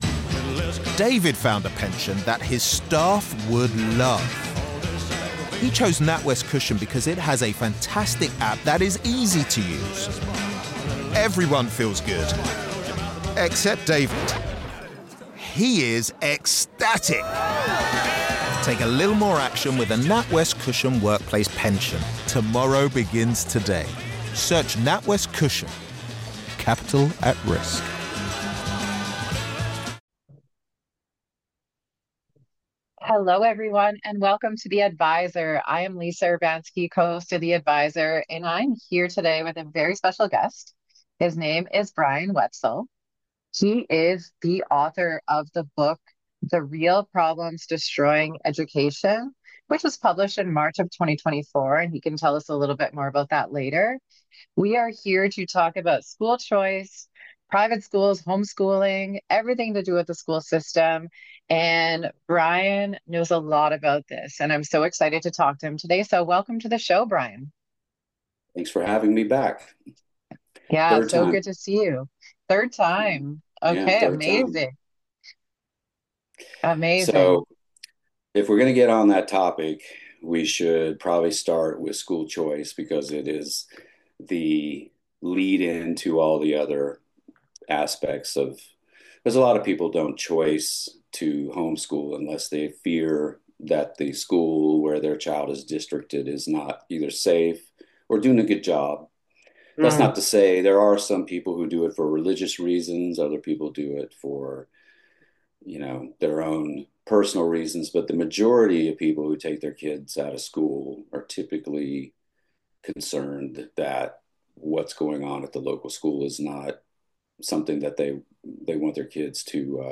this is the conversation you need to hear.